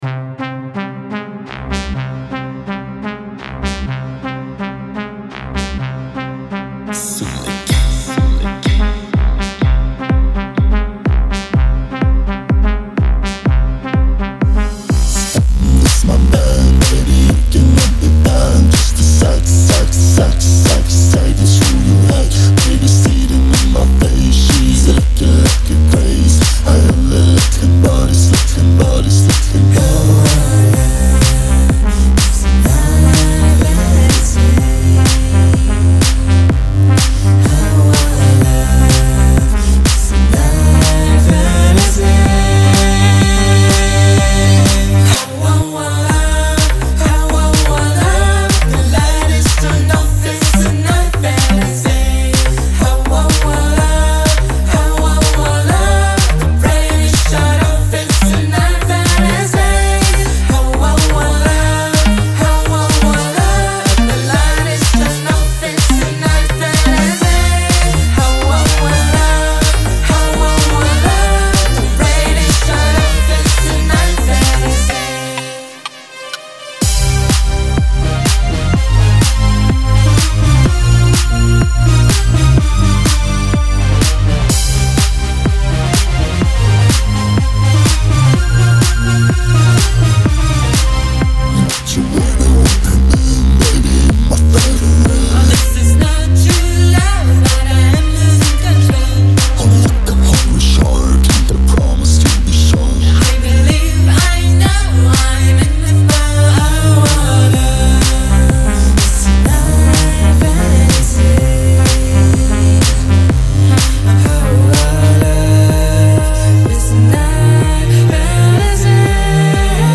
Или так ) У вас там нет элементарной работы с вокалами, надо ж выравнивать ручками по динамике, компрессором правильно обработать. Пространственная обработка какая-то странная, ревера не очень в тему и везде по разному еще накручено, всё короче плавает туда сюда и не собранно в единое целое.
На 3:04 вы там вообще здорово придумали, такой тоненький соло голос девочки и вдруг он звучит не в центре, а зачем-то раздут по стерео, вашу тётю и в левой и в правой колонке показывают )))